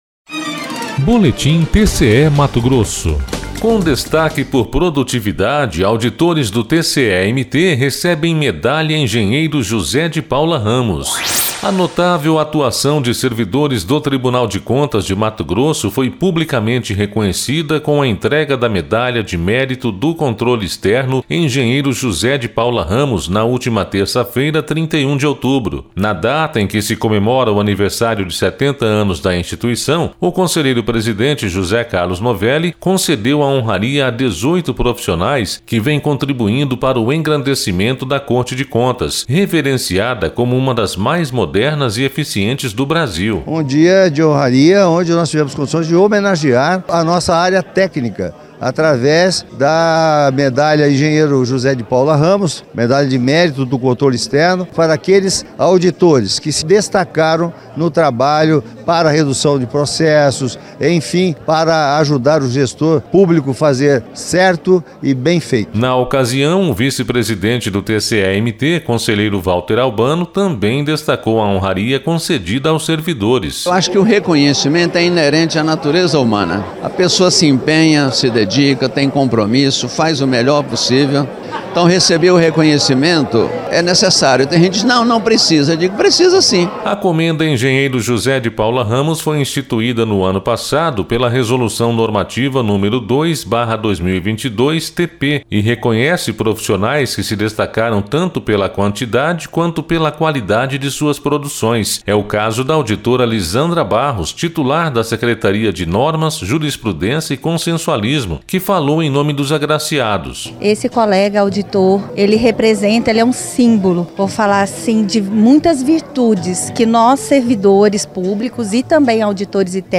Sonora: José Carlos Novelli – conselheiro presidente do TCE-MT
Sonora: Valter Albano – conselheiro vice-presidente do TCE-MT